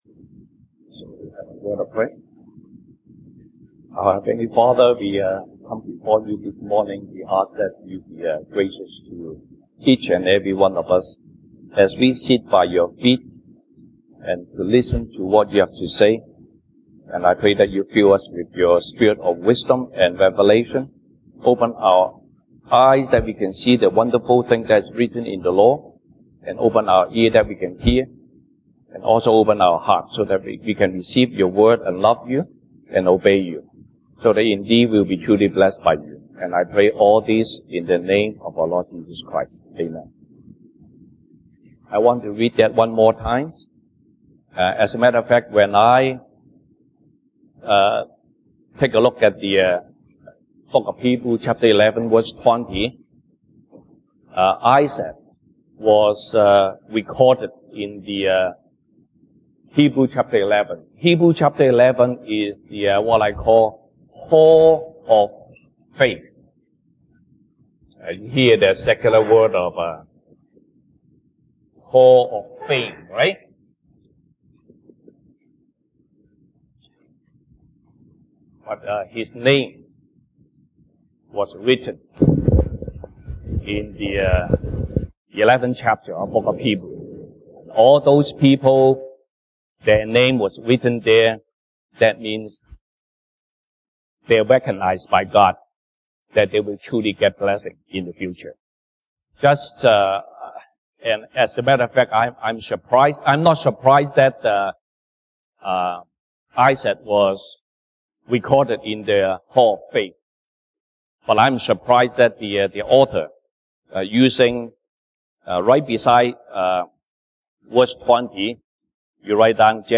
Sunday Service English Topics